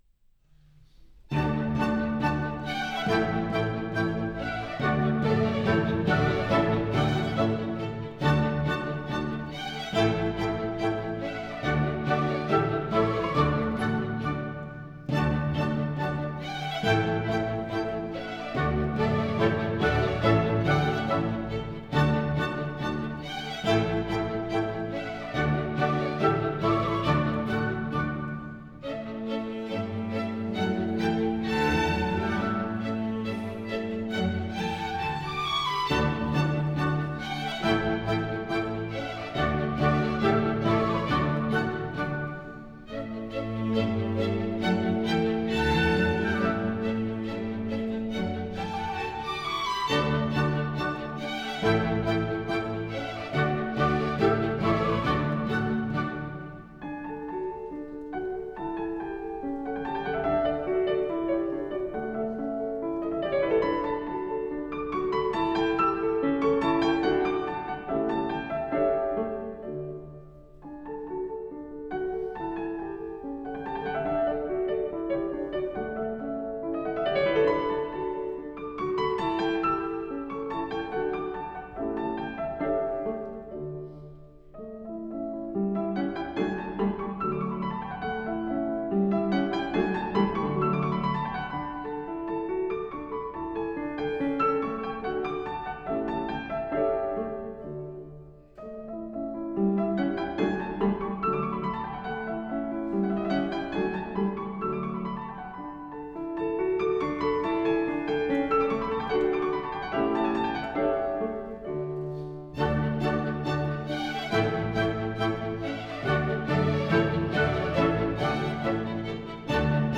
Piano Tonstudio
08-W_A_Mozart_KV382_Rondo-in-D-major_Piano-and-Orchestra_I_Alegretto_Grazioso_Dolby-Atmos-Binaural.wav